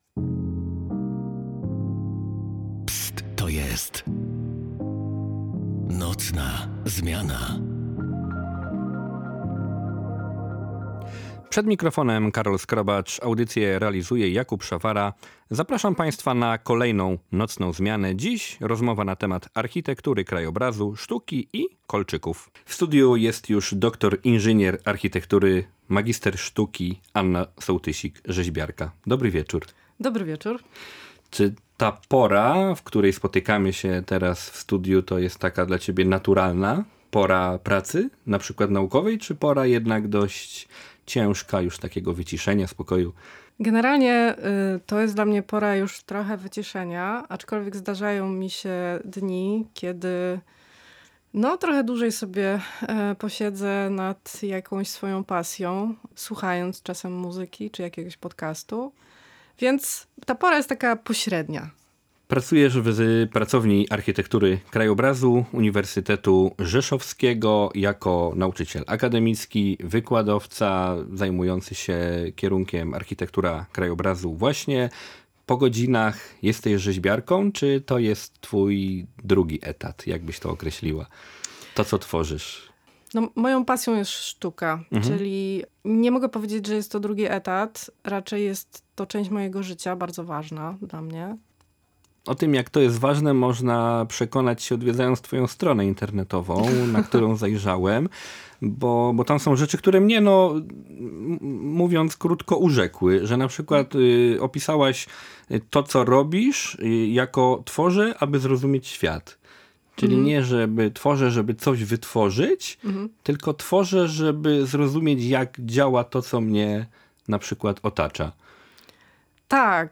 Nocne spotkanie w studiu Polskiego Radia Rzeszów obfitowało w różne wątki. Poruszony został ważny temat architektury krajobrazu – dyscypliny, z której specjaliści są coraz częściej poszukiwani.